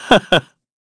Shakmeh-Vox_Happy1_kr.wav